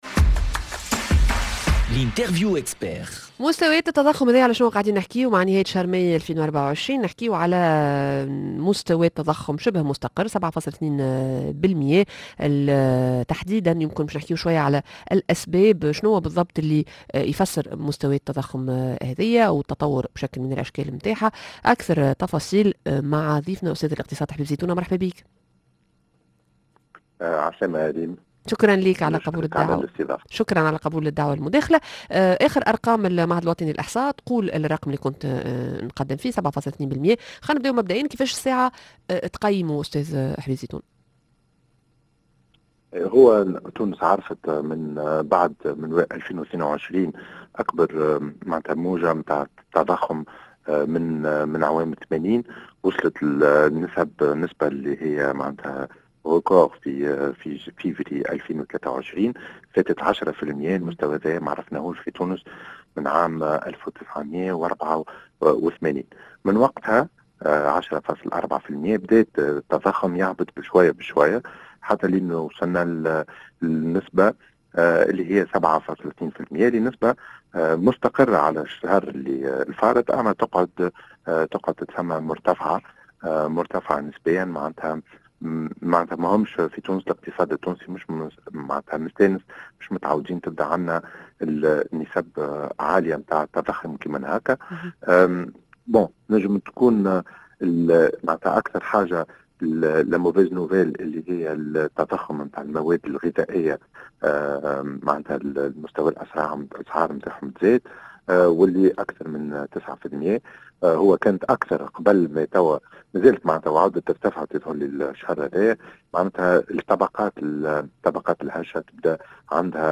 مستويات التضخم إلى حدود ماي 2024 التفاصيل مع ضيفنا عبر الهاتف